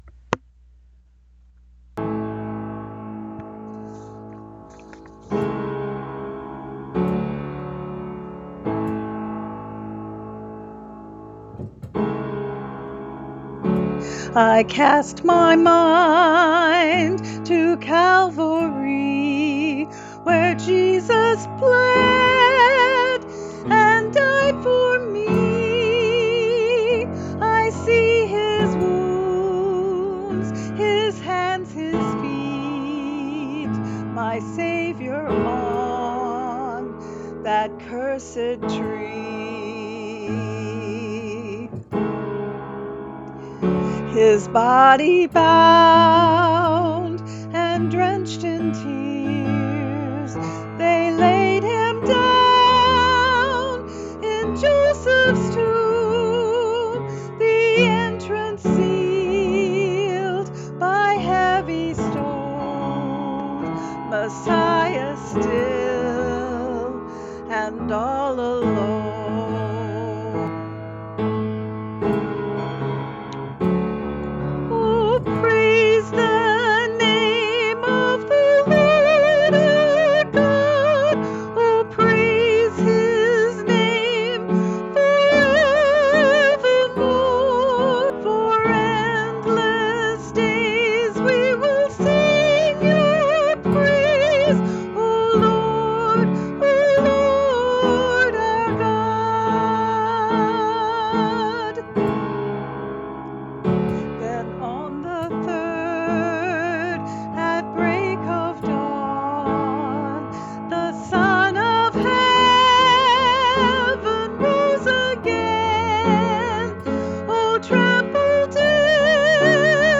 Vocalist
Keyboard